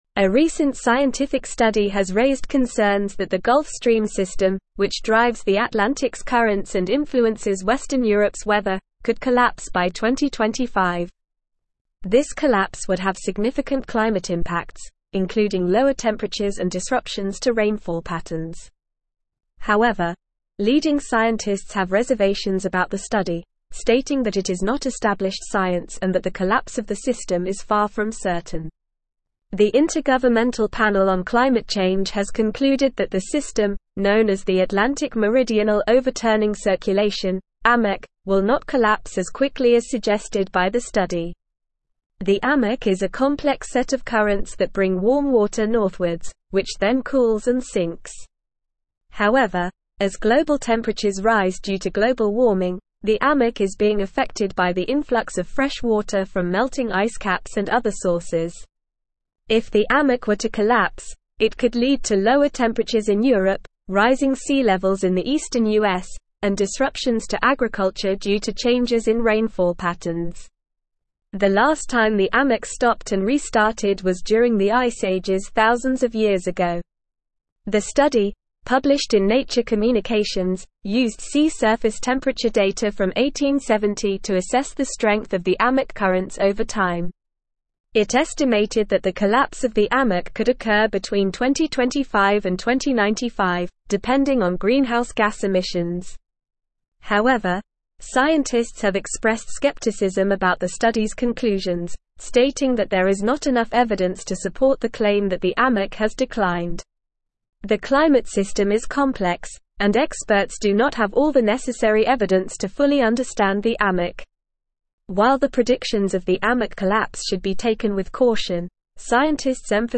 Normal
English-Newsroom-Advanced-NORMAL-Reading-Gulf-Stream-System-Could-Collapse-Scientists-Express-Reservations.mp3